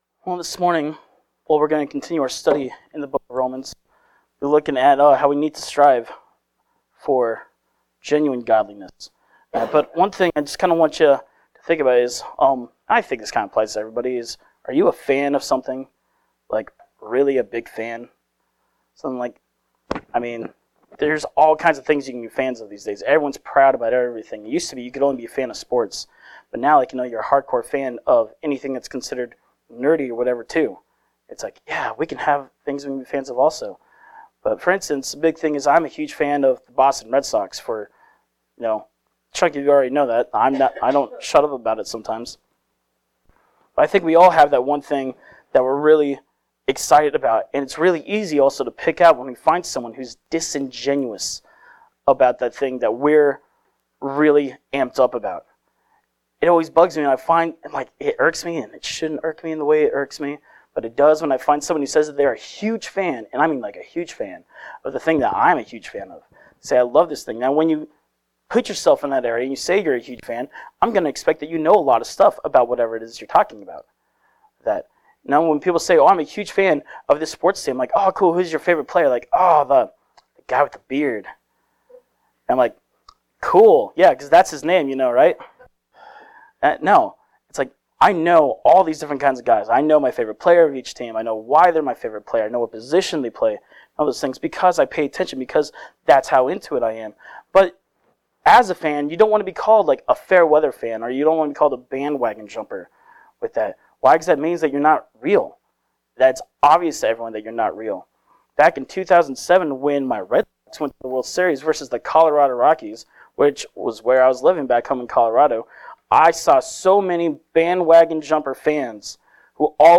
Service Type: Sunday Morning Worship
Please note: Some of the audio cuts out during the recording.